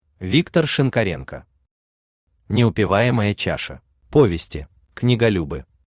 Аудиокнига Неупиваемая чаша | Библиотека аудиокниг
Aудиокнига Неупиваемая чаша Автор Виктор Шинкоренко Читает аудиокнигу Авточтец ЛитРес.